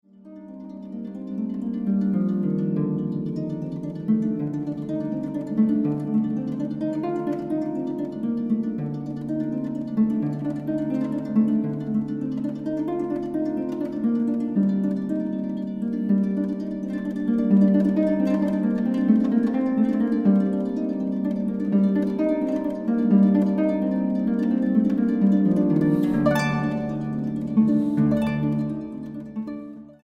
arpa.